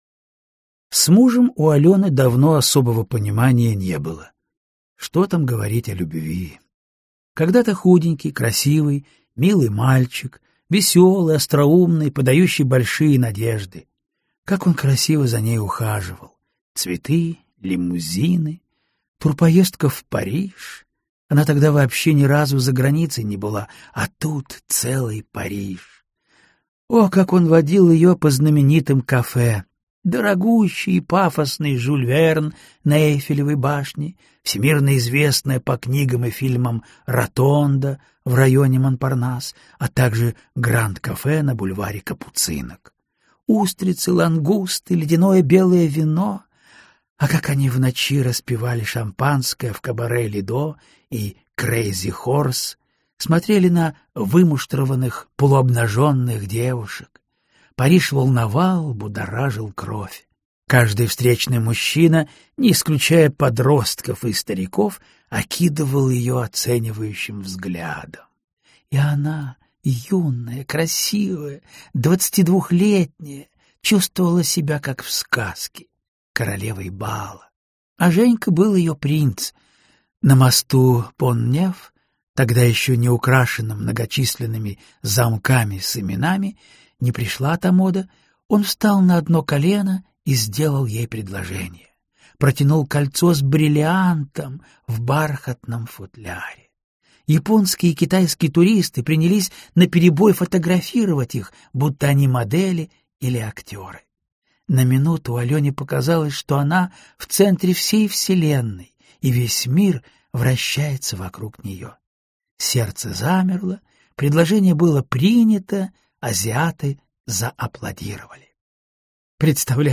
Прослушать фрагмент аудиокниги Слишком много любовников Анна Литвиновы Произведений: 14 Скачать бесплатно книгу Скачать в MP3 Вы скачиваете фрагмент книги, предоставленный издательством